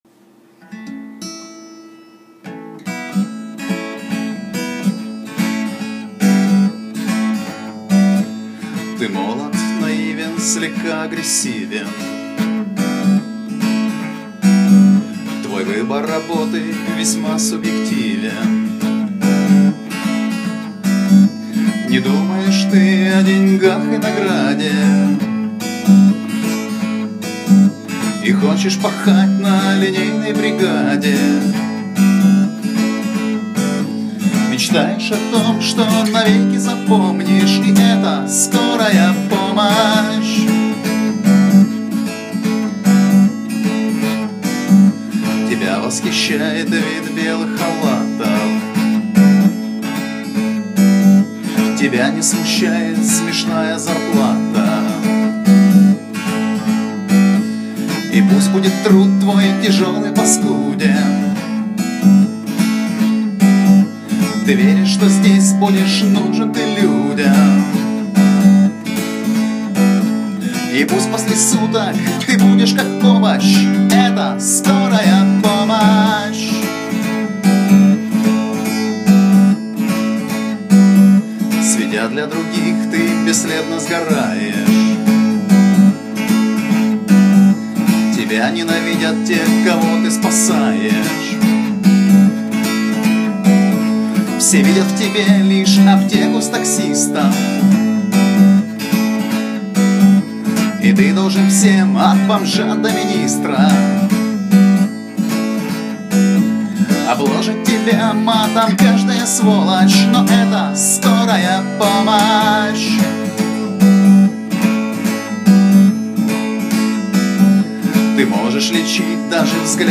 Скорая помощь Немного скоропомощного романтизма-патриотизма в блюзовой манер Слушать: Тег audio не поддерживается вашим браузером.